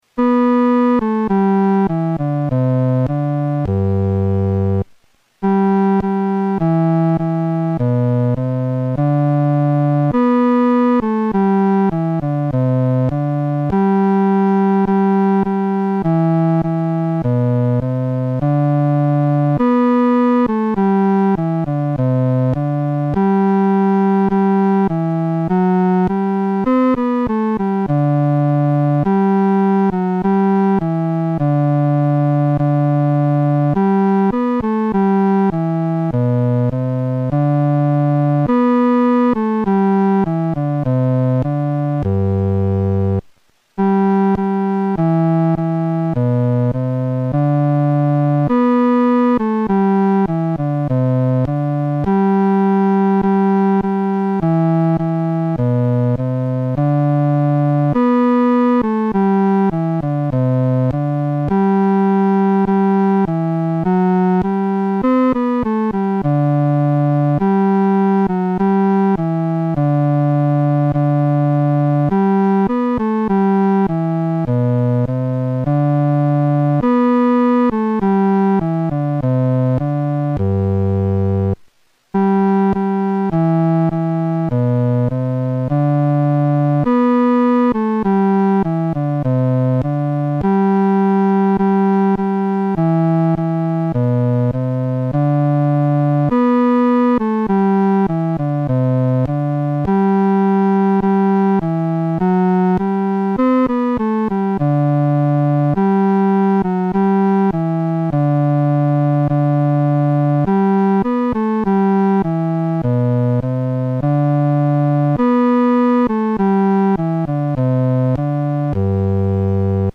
伴奏
男低
本首圣诗由网上圣诗班录制